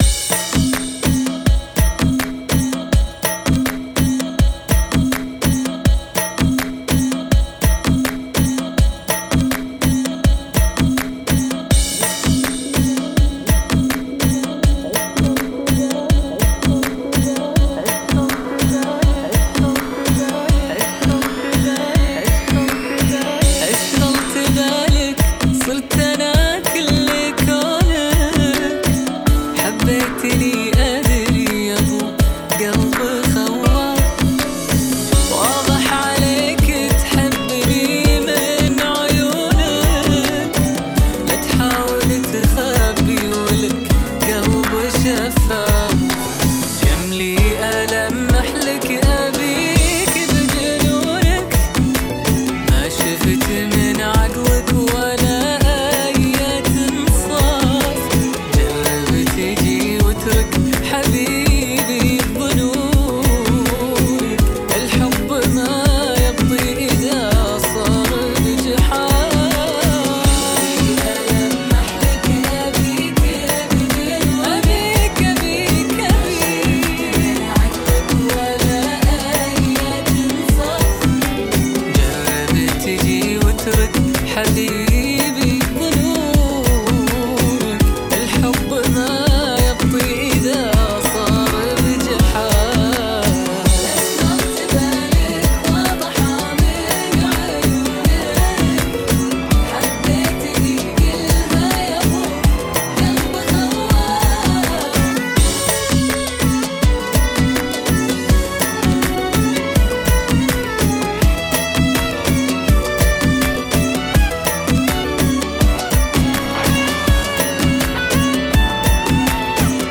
No Drop